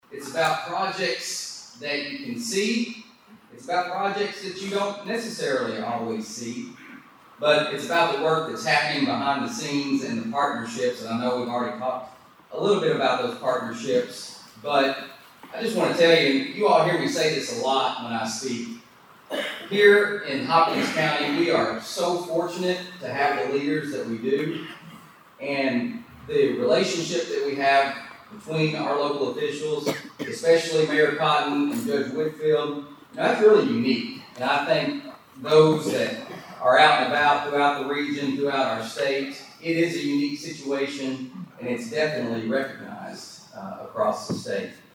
State of the Cities and County Address Held Thursday
Local officials and community members gathered this morning for an update on key issues and developments affecting Hopkins County and its cities.